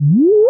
alarm_siren_loop_10.wav